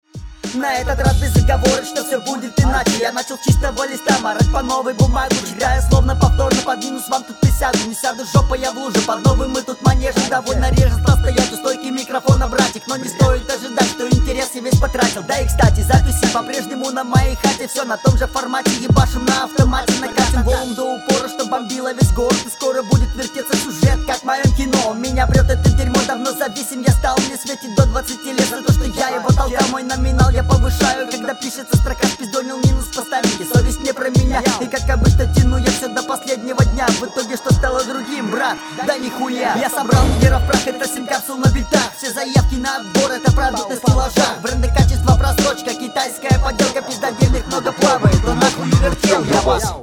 Флоу неплохой, голосом не идеально владеешь, отточи этот момент.
Еееее, старая школа